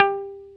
Index of /90_sSampleCDs/Roland L-CDX-01/GTR_Dan Electro/GTR_Dan-O 6 Str